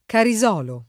Carisolo [ kari @0 lo ]